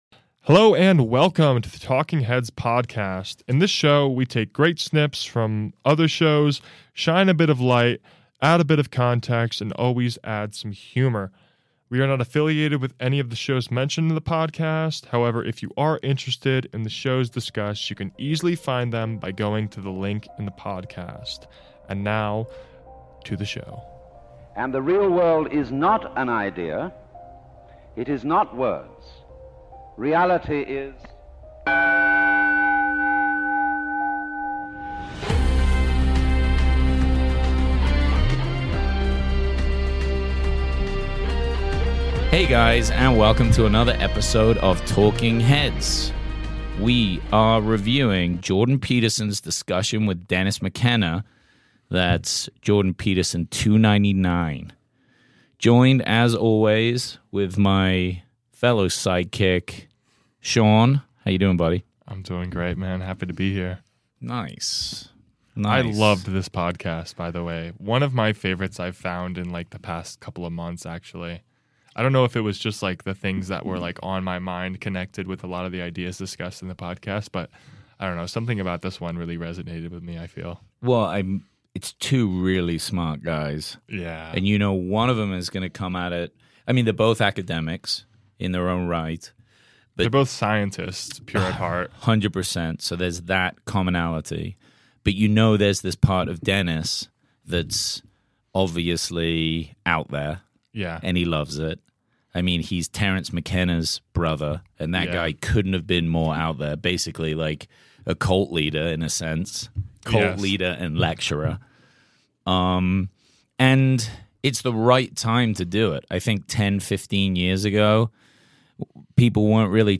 Talking Heads Podcast is a review show to help you find the best podcasts of the month.